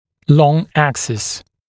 [lɔŋ ‘æksɪs][лон ‘эксис]длинная ось (зуба)